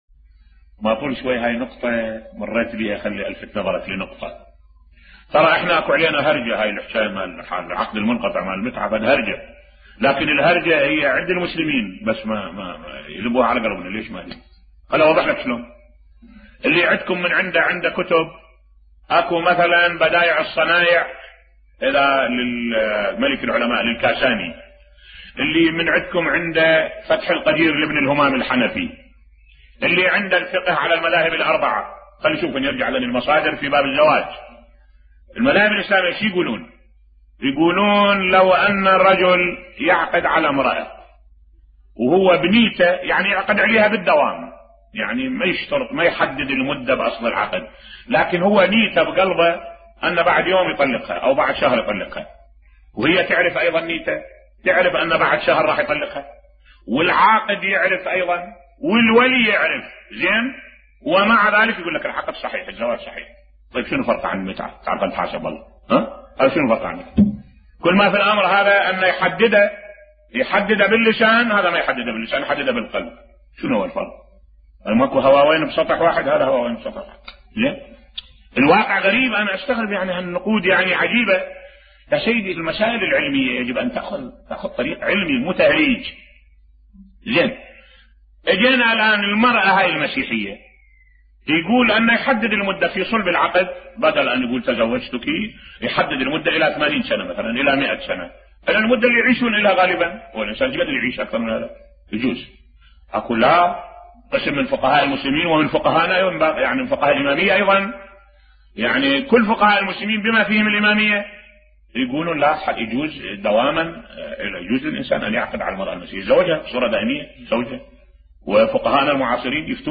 ملف صوتی زواج المتعة وتهريج القوم عليه بصوت الشيخ الدكتور أحمد الوائلي